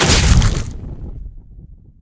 Roots_hit.ogg